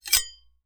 Metal_81.wav